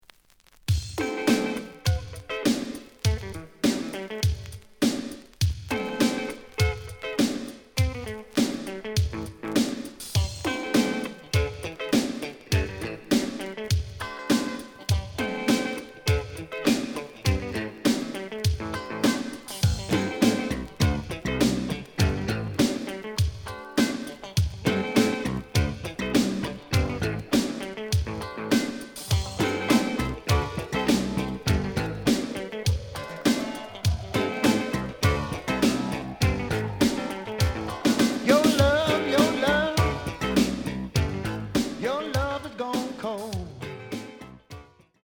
The audio sample is recorded from the actual item.
●Format: 7 inch
●Genre: Blues